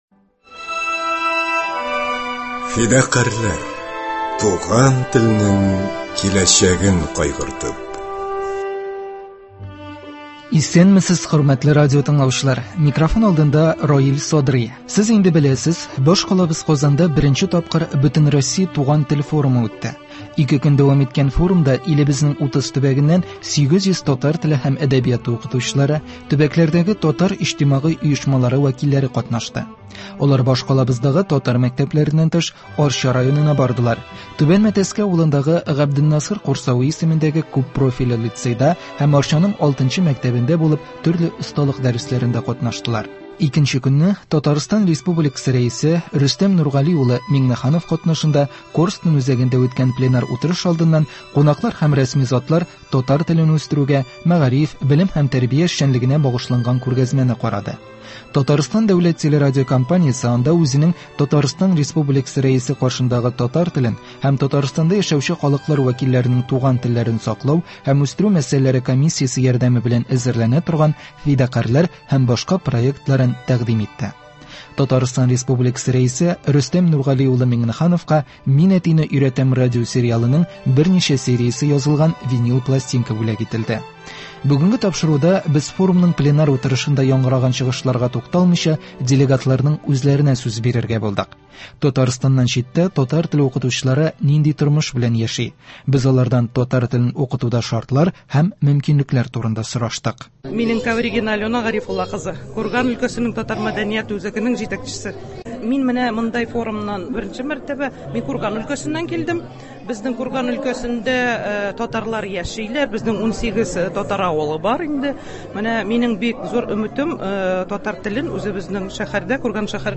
Бүгенге тапшыруда без форумның пленар утырышында яңгыраган чыгышларга тукталмыйча, делегатларның үзләренә сүз бирергә булдык. Татарстаннан читтә татар теле укытучылары нинди тормыш белән яши? Без алардан татар телен укытуда шартлар һәм мөмкинлекләр турында сораштык.